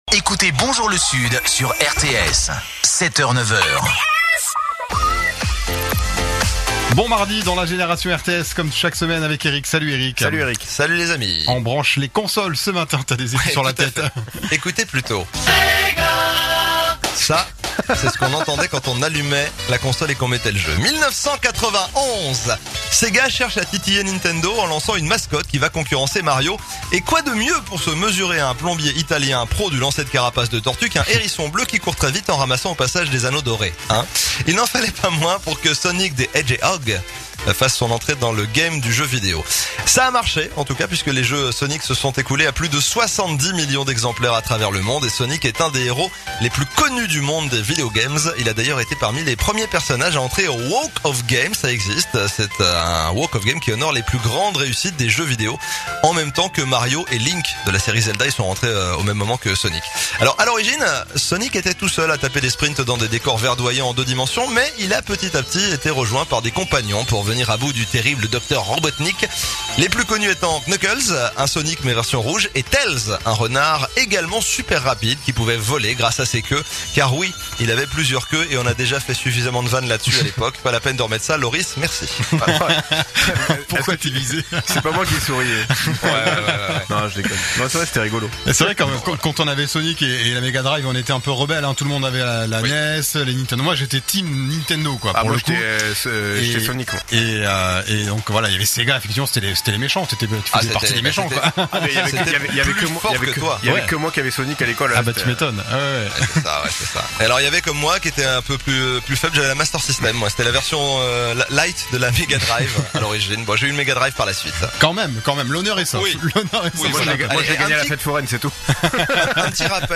2. Les rubriques enregistrées dans les conditions du direct avec un ou plusieurs de vos animateurs
Pour plus de naturel, de convivialité et d’interaction, la rubrique est enregistrée dans les conditions du direct avec le ou les animateurs de la tranche dans laquelle elle est diffusée, grâce à une interface dont nous vous fournissons le lien.
Bien que l’enregistrement se fasse à distance, l’illusion est parfaite pour l’auditeur qui a l’impression que tout le monde est dans le même studio.